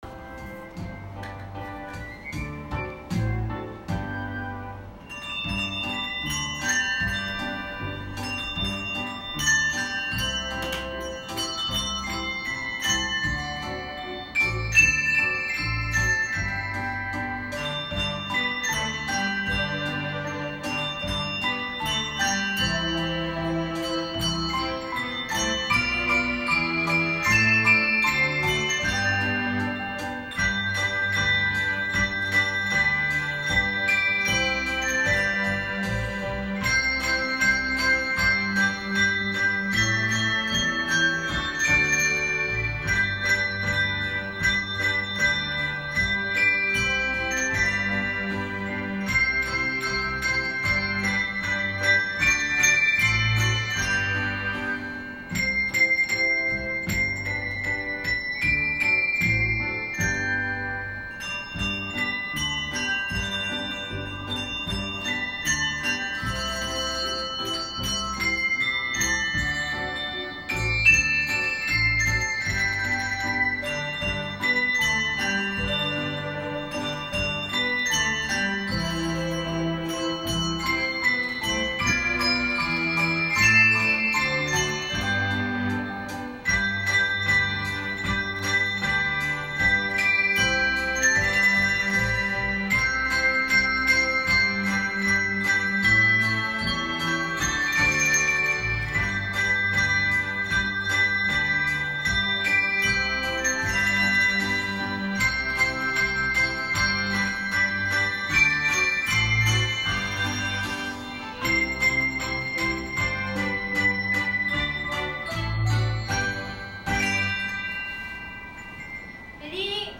メリー　クリスマス～ハンドベル発表会（音付き）～
朝会で学年別で3曲、ハンドベル発表会がありました。
011223入中3年ハンドベル.m4a